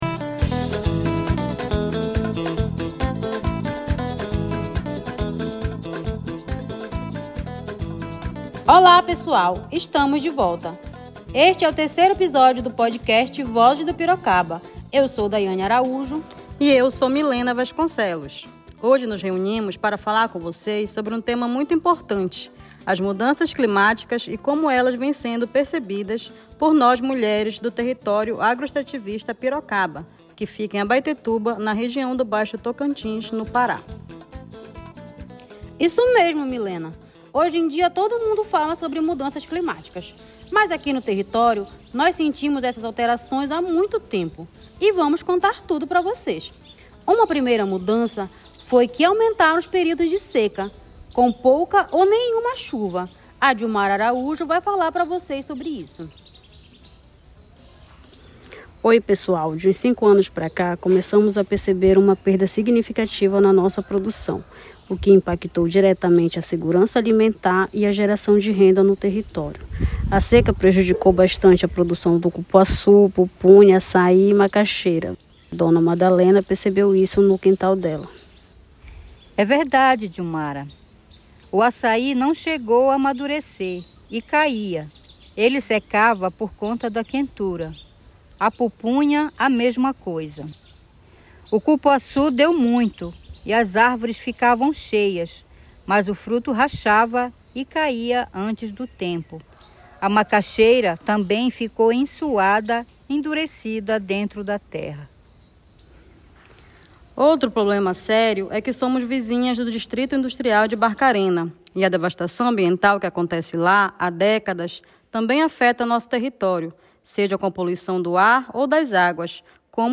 Produção e narração: Mulheres integrantes do ASAPAP